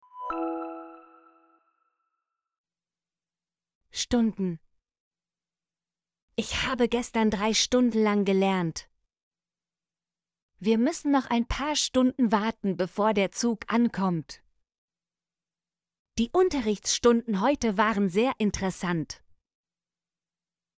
stunden - Example Sentences & Pronunciation, German Frequency List